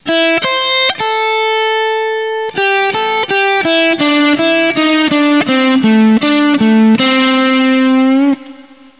וקצת יותר לאט